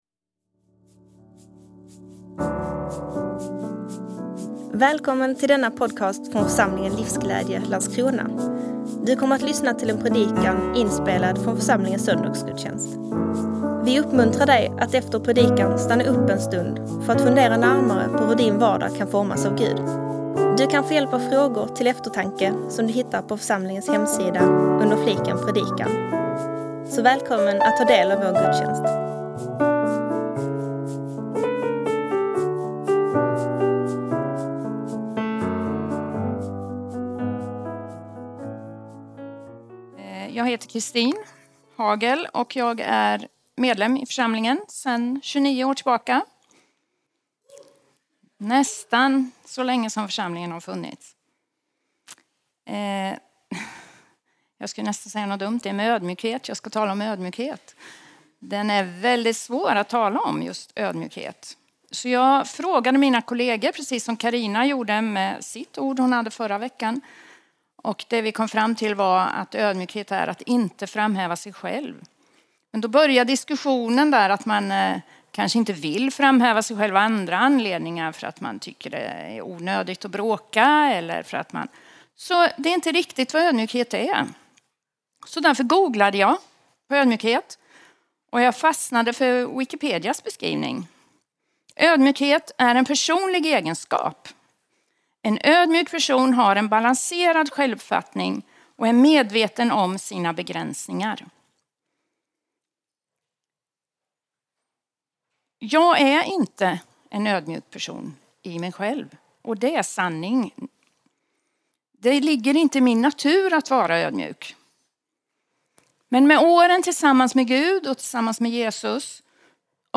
Predikant